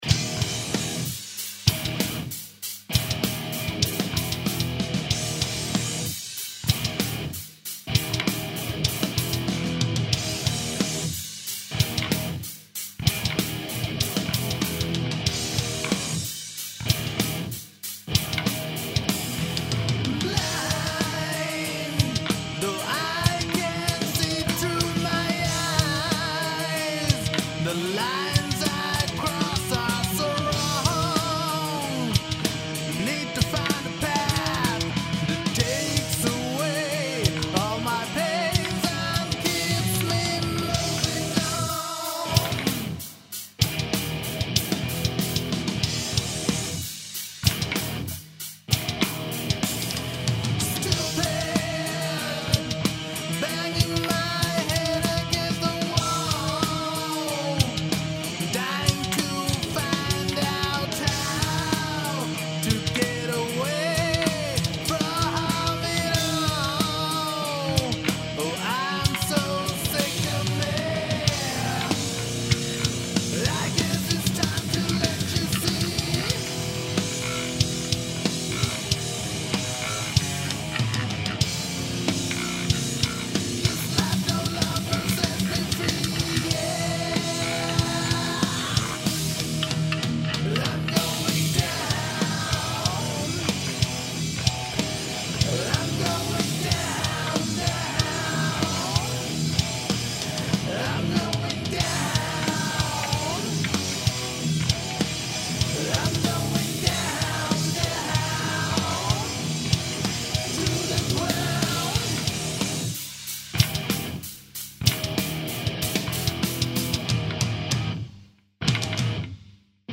Heute im Proberaum fertig geworden